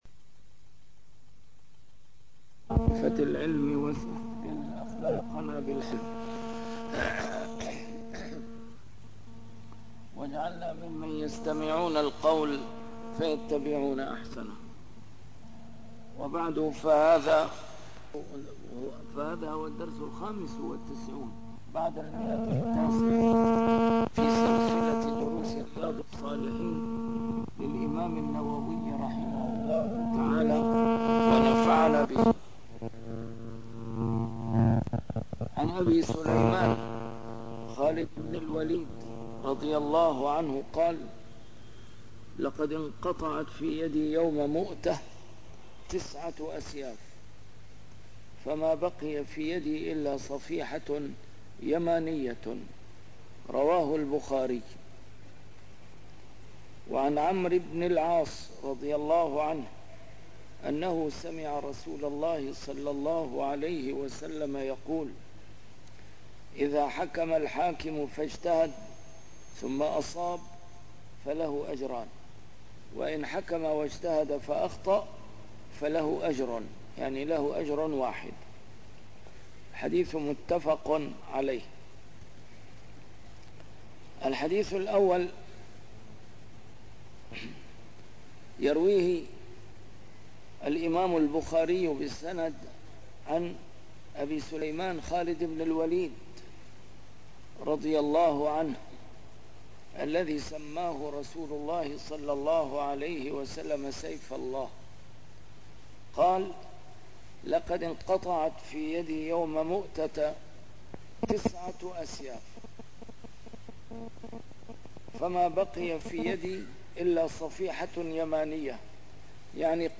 A MARTYR SCHOLAR: IMAM MUHAMMAD SAEED RAMADAN AL-BOUTI - الدروس العلمية - شرح كتاب رياض الصالحين - 995- شرح رياض الصالحين: بابُ المنثورات والمُلَح